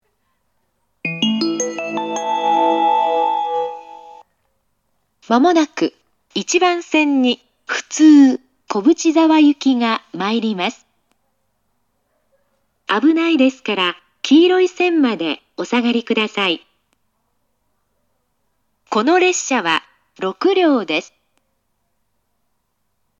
接近放送
普通　小淵沢行（6両編成）の接近放送です。